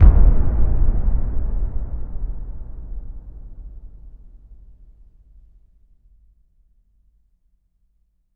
LC IMP SLAM 7A.WAV